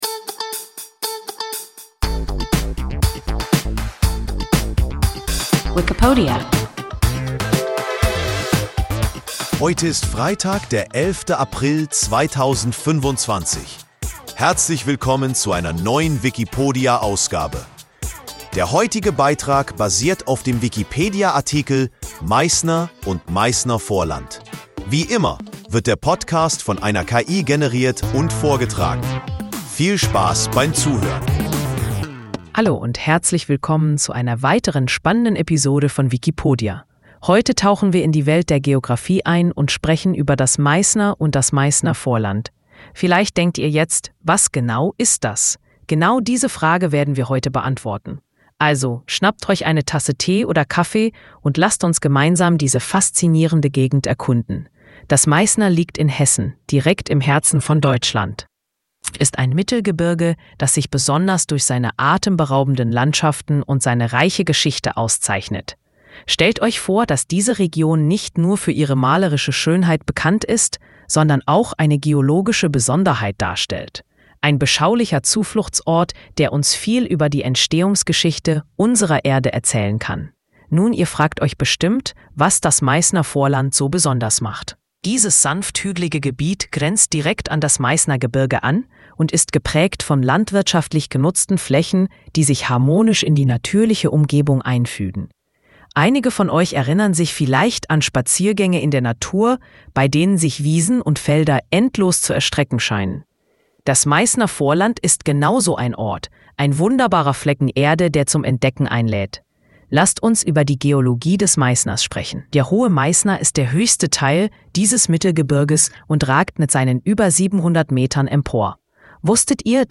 Wikipodia – ein KI Podcast